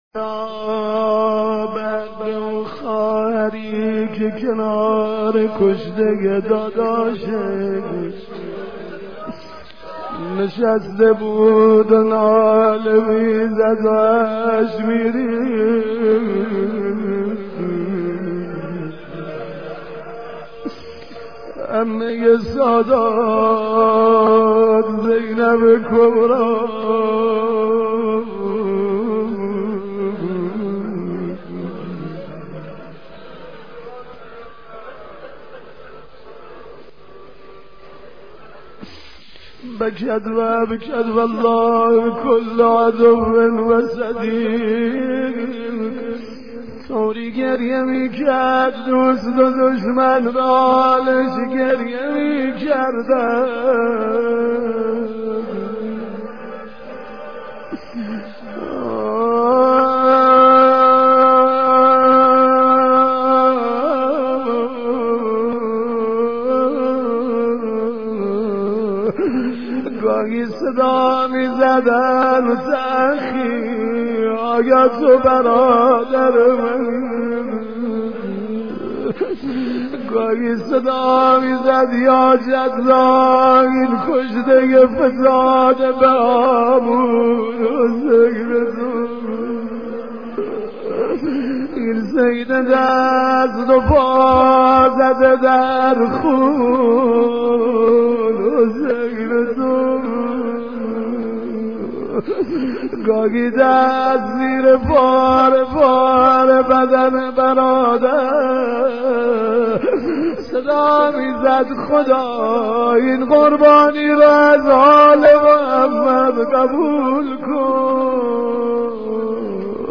مداحی صوتی